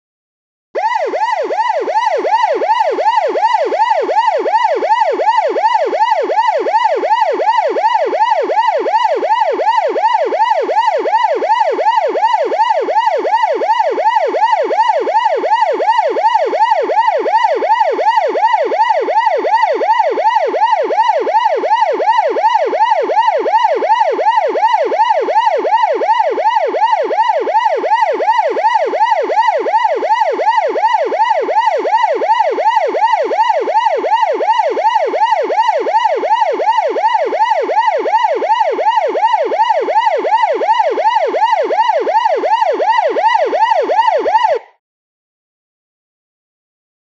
Siren; English Police Siren Constant And Stop. New Yelper Type.